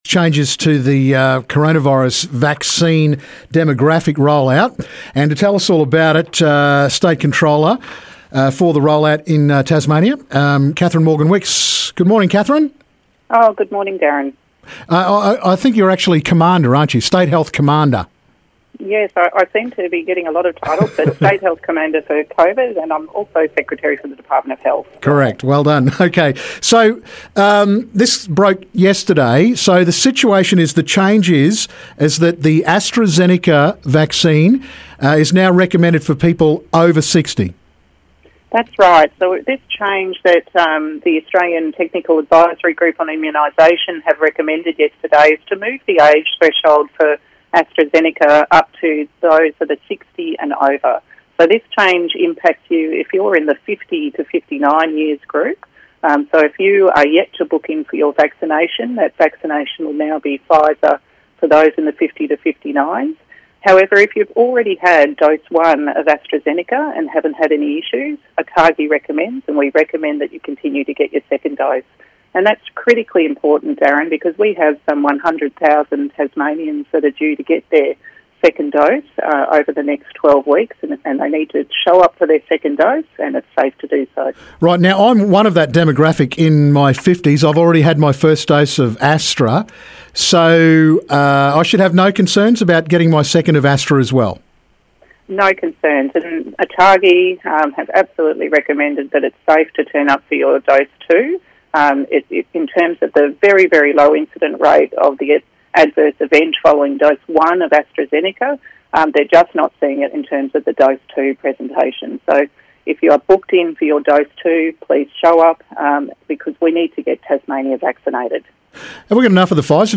Changes for the CV vaccine rollout. State Health Commander Katherine Morgan Wicks has details.